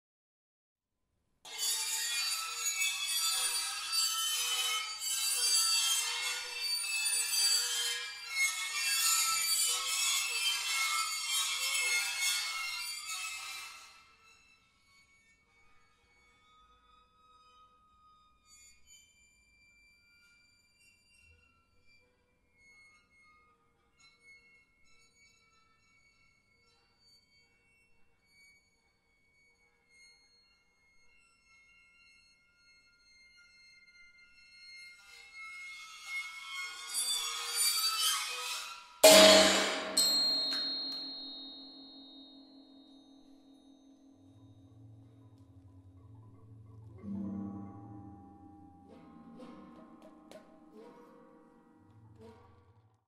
After this obstacle, turbulence is represented by soft rattles, scratches, tremolo and all manner of eddies and whirlpools, all which decay over time (see fig. 21 and sound 9).
Der Rufer, turbulence, to an obstacle.